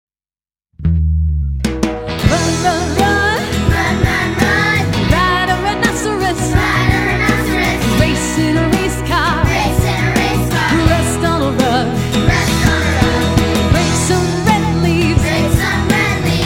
A copy cat song!